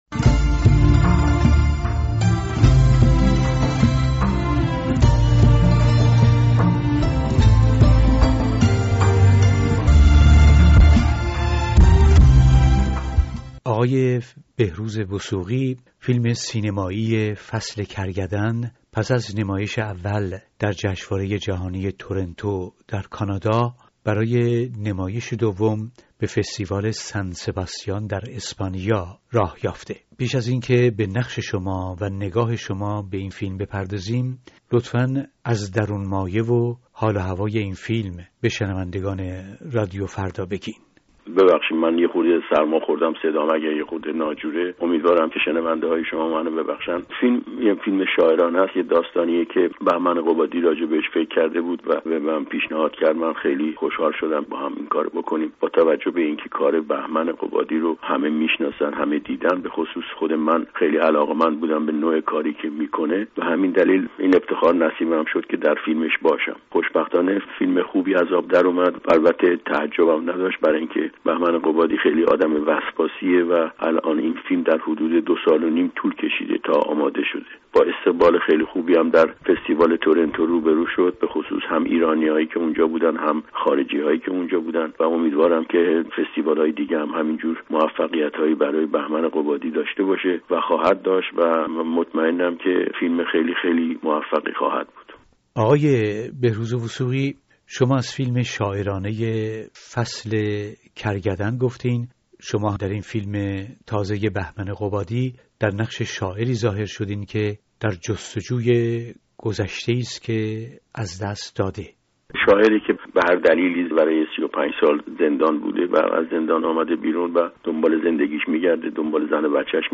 گفتگوی رادیو فردا با بهروز وثوقی